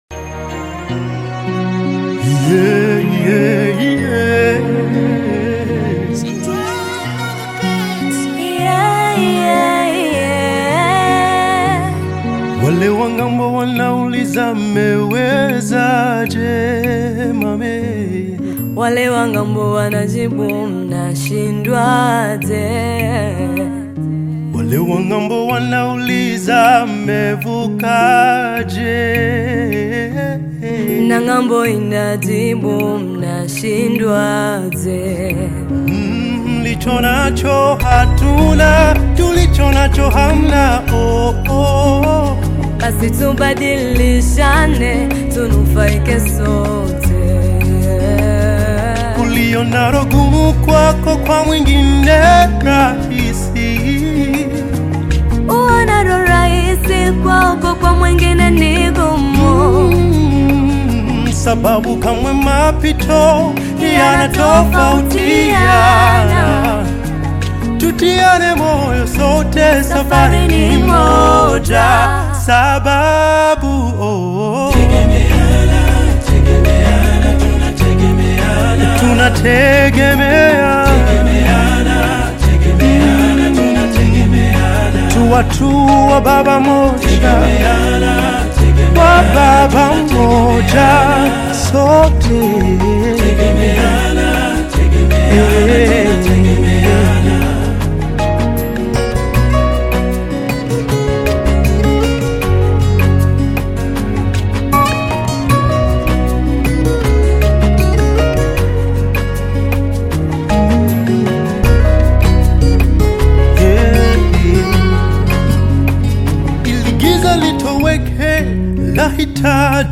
Tanzanian Gospel artist, singer, and songwriter
motivational song